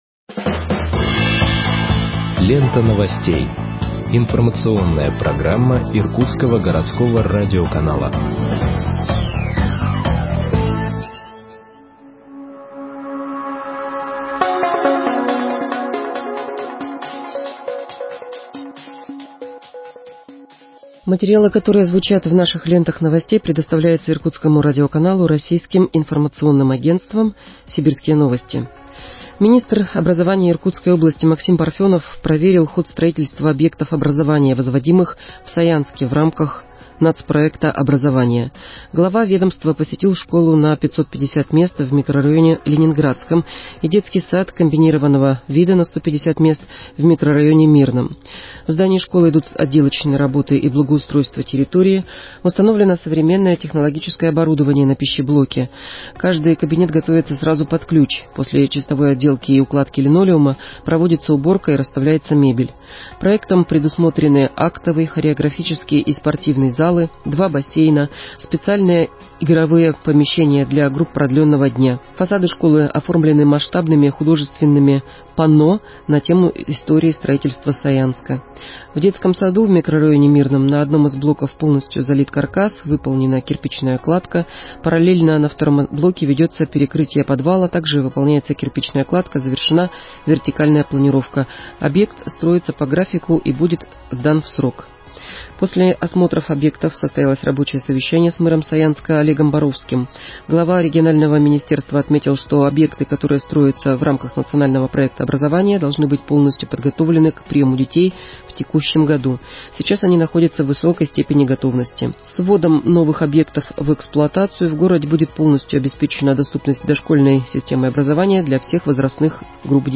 Выпуск новостей в подкастах газеты Иркутск от 13.07.2021 № 1